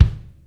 kick 8.wav